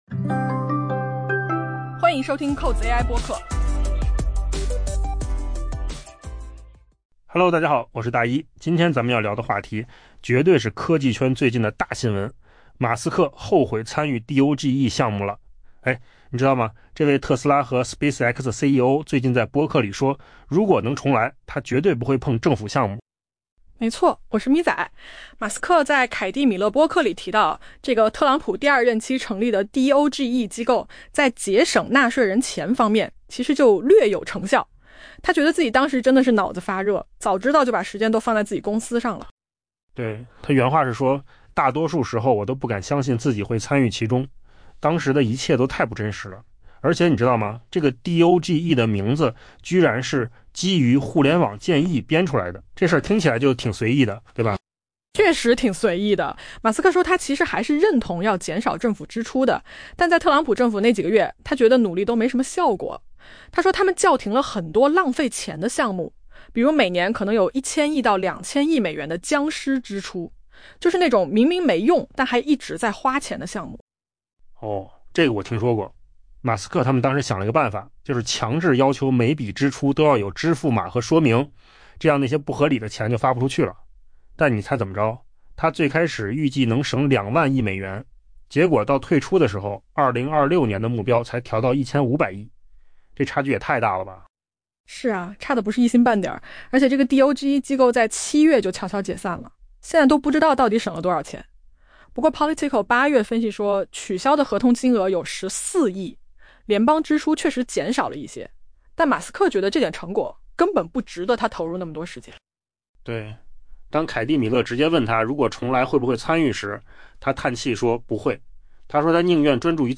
AI 播客：换个方式听新闻 下载 mp3 音频由扣子空间生成 马斯克近日罕见地对政府效率部 （DOGE） 今年早些时候的表现发表评论。